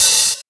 • Damped Hi Hat One Shot D# Key 46.wav
Royality free hat one shot tuned to the D# note. Loudest frequency: 6844Hz
damped-hi-hat-one-shot-d-sharp-key-46-xK8.wav